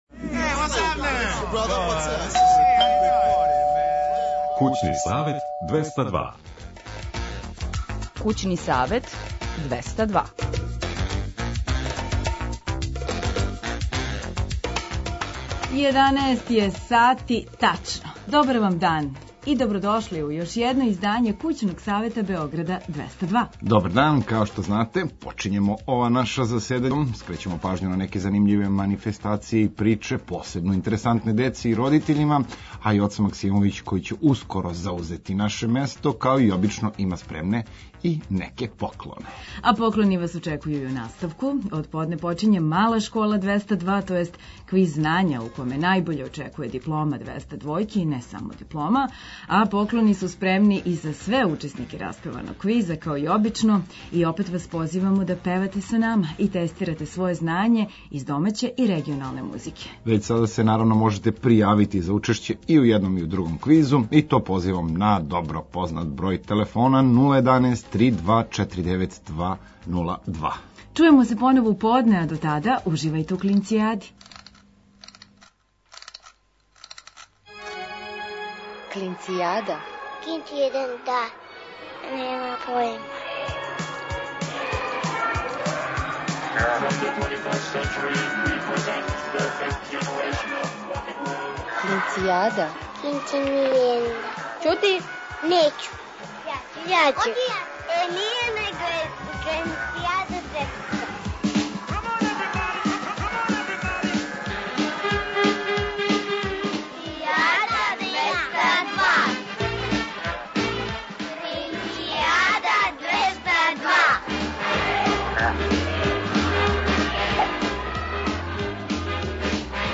Ново заседање „Кућног савета” преносимо уживо!